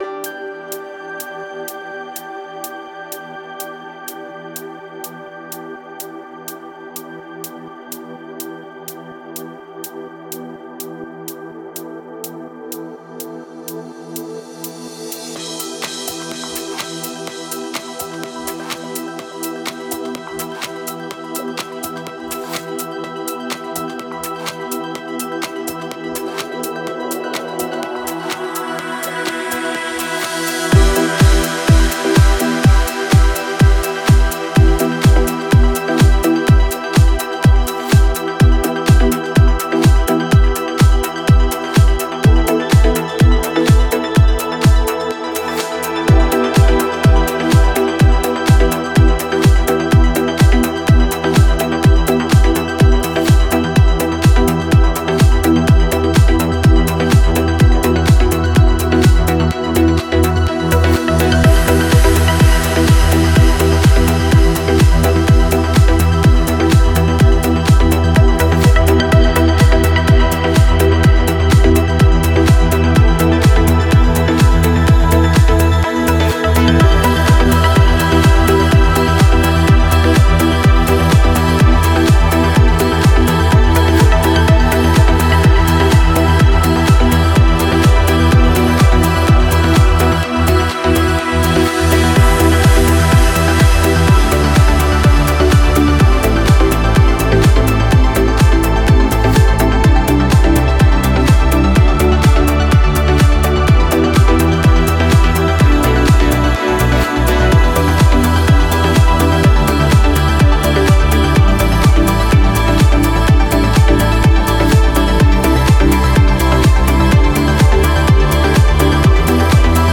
Стиль: Progressive House / Melodic Progressive